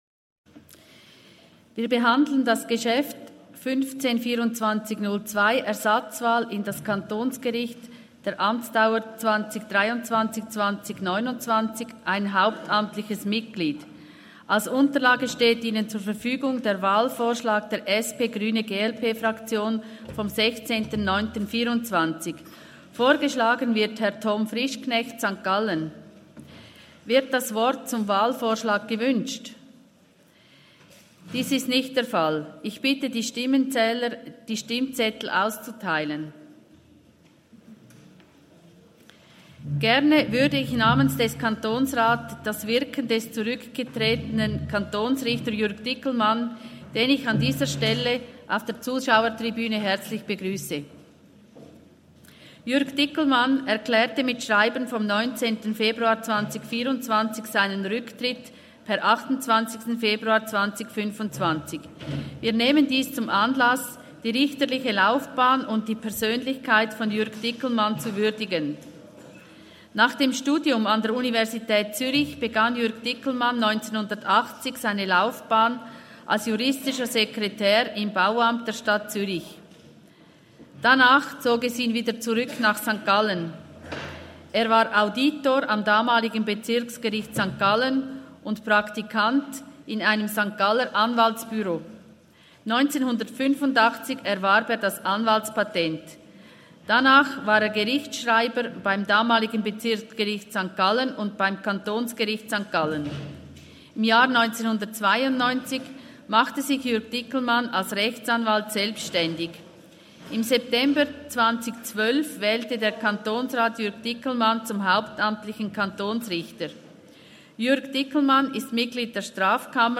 17.9.2024Wortmeldung
Session des Kantonsrates vom 16. bis 18. September 2024, Herbstsession